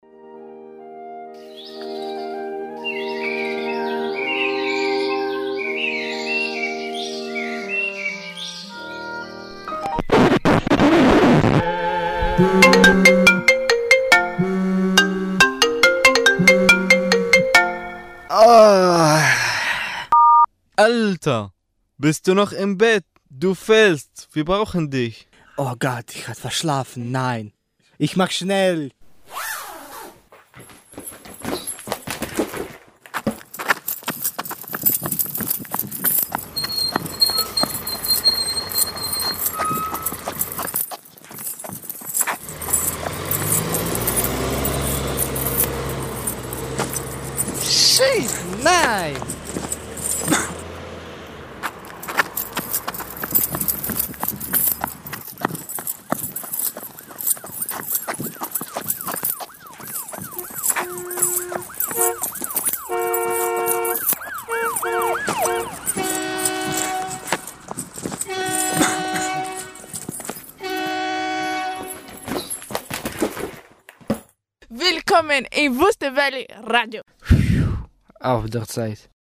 Die Jingles von Radio Grenzenlos erzählen alle eine kurze Geschichte mit Geräuschen, Sprache und Musik mit einem Bezug zum Radioprojekt.
Der Protagonist des zweiten Jingles schreckt aus seinen süßen morgendlichen Träumen hoch und bekommt mitgeteilt, dass er einen wichtigen Termin im Radio versäumt hat und begibt sich auf einen hektischen Laufweg durch die Geräuschkulisse des städtischen Verkehrs und kommt gerade noch rechtzeitig.
45895_Jingle_Verschlafen.mp3